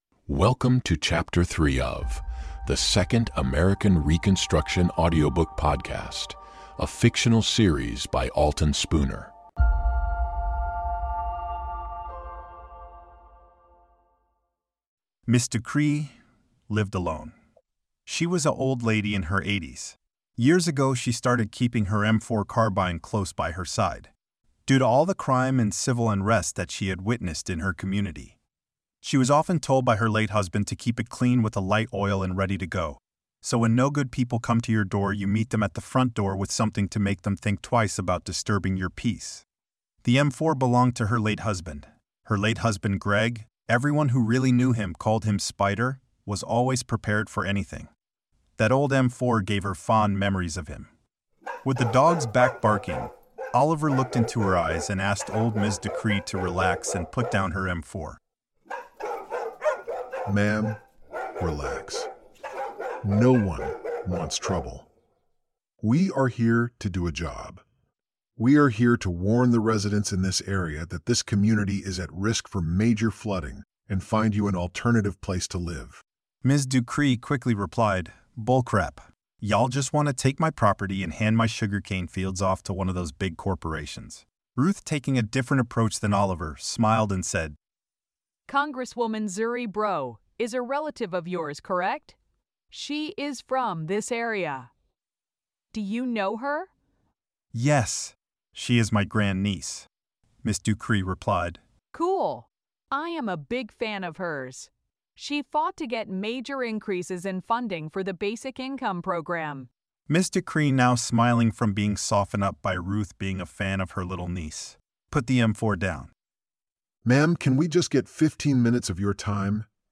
Format: Audio Book
Voices: Machine generated
Narrator: Third Person
Soundscape: Voices only